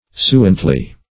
suently - definition of suently - synonyms, pronunciation, spelling from Free Dictionary Search Result for " suently" : The Collaborative International Dictionary of English v.0.48: Suently \Su"ent*ly\, adv. Evenly; smoothly.